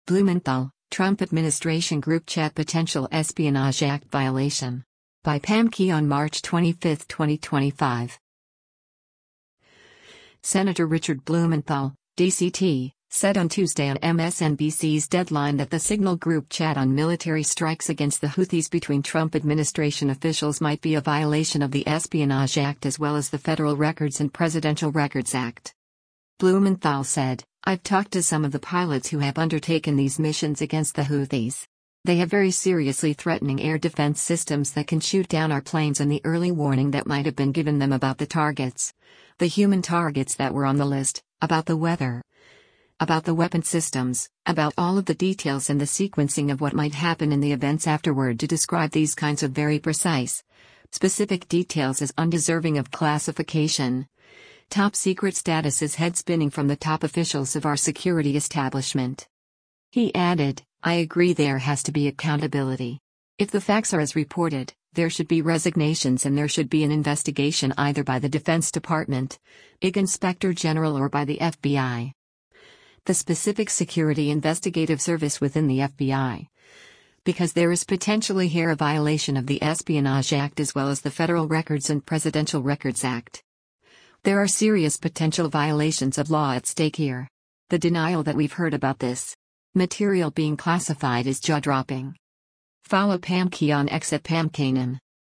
Senator Richard Blumenthal (D-CT) said on Tuesday on MSNBC’s “Deadline” that the Signal group chat on military strikes against the Houthis between Trump administration officials might be a violation of the “Espionage Act as well as the Federal Records and Presidential Records Act.”